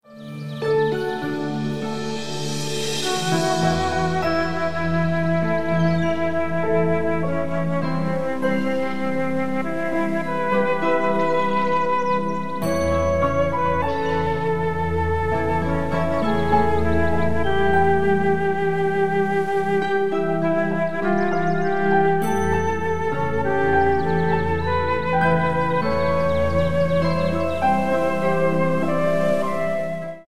50 BPM